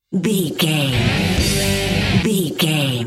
Aeolian/Minor
drums
electric guitar
bass guitar
hard rock
aggressive
energetic
intense
powerful
nu metal
alternative metal